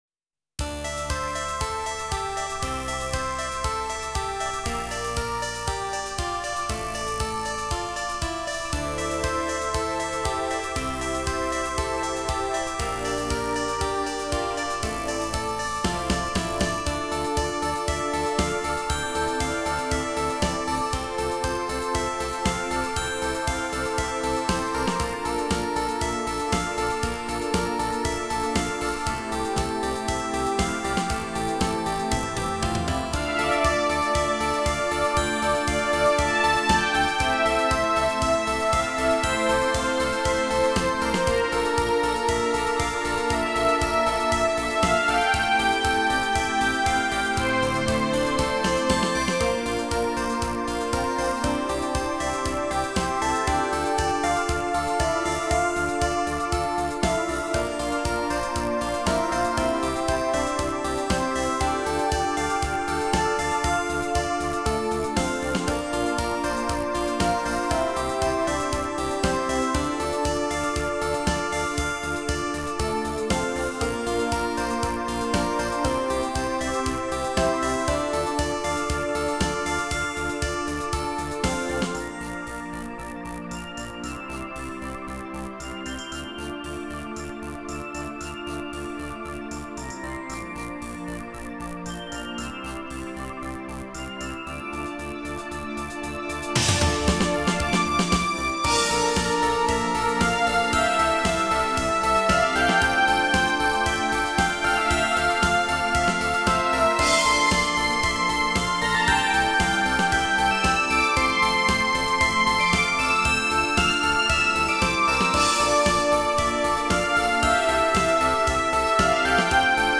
某ゲームのバッドエンドのアレンジです。
録音環境？44.1kHz/16bitです(無意味)
他のパートも基本その4台が鳴らしてます。(たぶん)
今聞くと色々と物足らない感がありますがそこはアマチュアのご愛嬌ってことでｗ